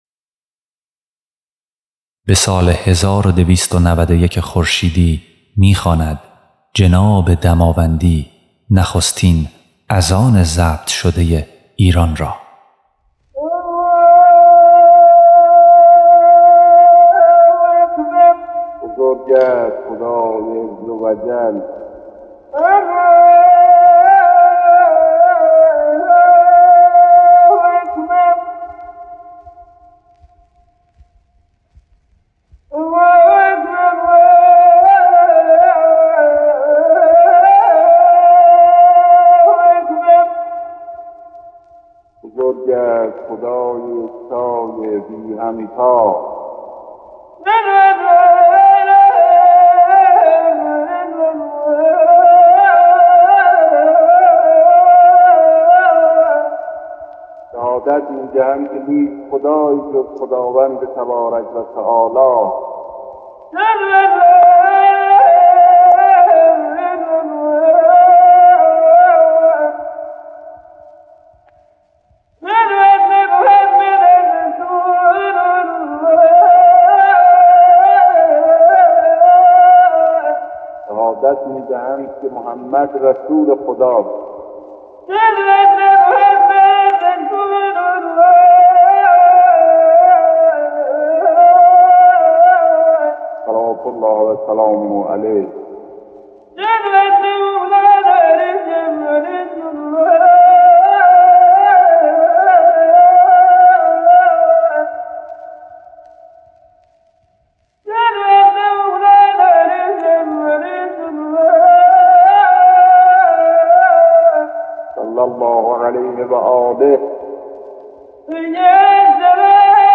بدين ترتيب او تصميم به ضبط اذاني مي‌گيرد که نه فقط به عنوان نخستين اذان ضبط شده ايران شناخته مي‌شود بلکه به دليل نوآوري که اين هنرمند در استفاده از دستگاه بيات ترک و افزودن ترجمه فارسي به متن عربي آن داشته، زمينه تبديل اين ديسک به پرفروش‌ترين صفحه گرامافون 78 دور تاريخ کشور را فراهم آيد.
صداي گرم و لحن تاثيرگذار ميرزا اسداله‌خان اتابکي ترجمه فارسي اين اذان را گيراتر نموده ‌است.
مؤذن‌
ترجمه اذان